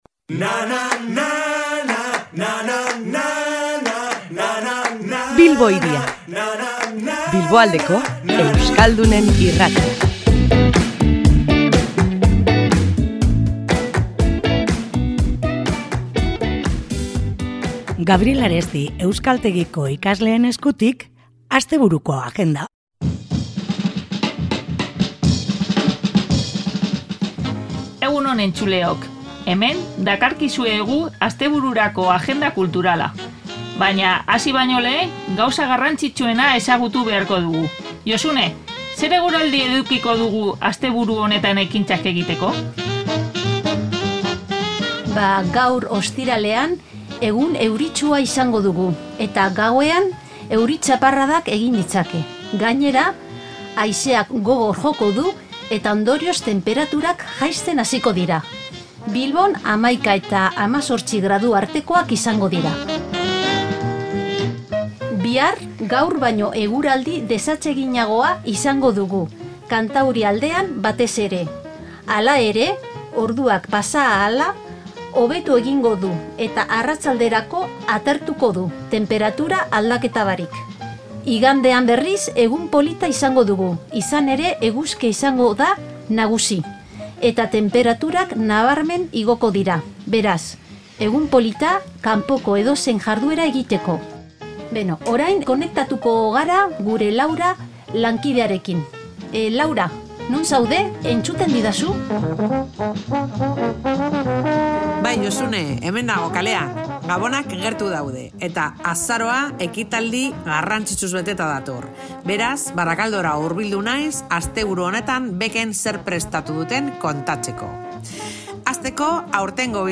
Gabriel Aresti euskaltegiko ikasleak izan ditugu gurean, eta astebururako planak ekarri dizkigute Bilbo Hiria irratira. Musika, antzerkia, zinema eta kultur ekitaldi ugari izango dira hirian datozen egunotan.